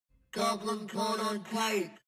Goblin Sound Effects MP3 Download Free - Quick Sounds